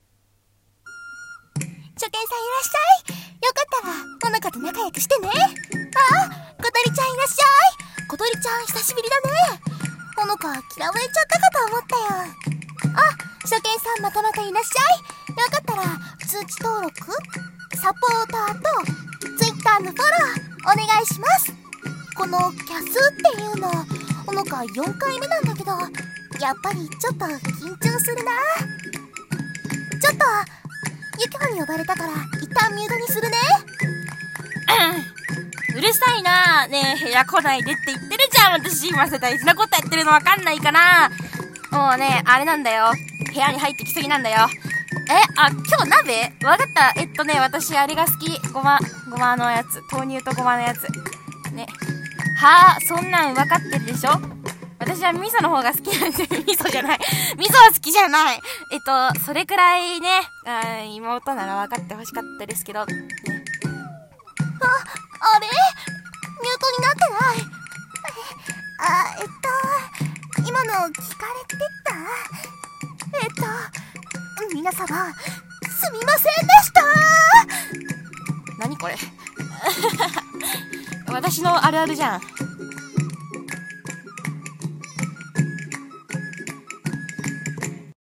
エセ穂乃果が地声バレる【1人声劇】